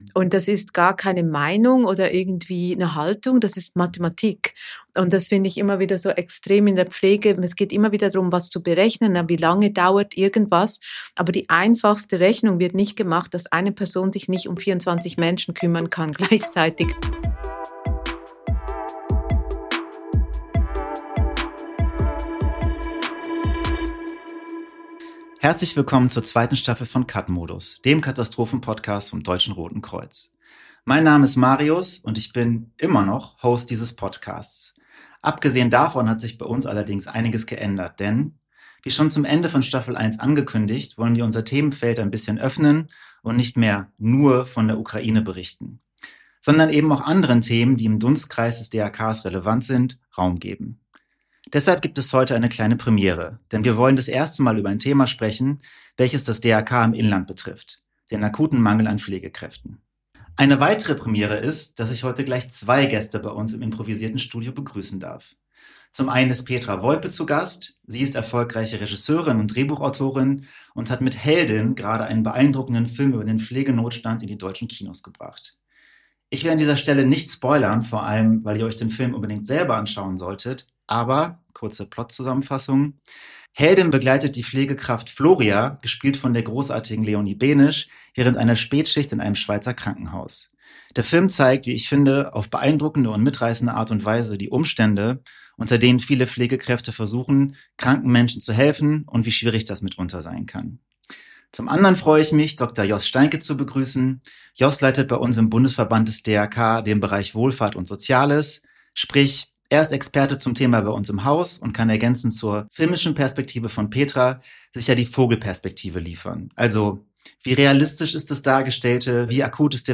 Beschreibung vor 11 Monaten Doppelte Premiere zum Auftakt der zweiten Staffel: Nicht nur sprechen wir das erste Mal über ein Thema, das das DRK im Inland betrifft, sondern, wir haben auch gleich zwei Gäste vor den Mikrofonen.